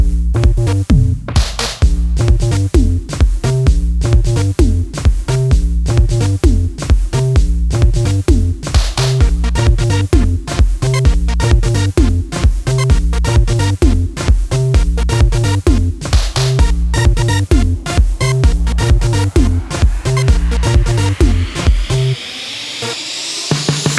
for male rapper solo R'n'B / Hip Hop 3:15 Buy £1.50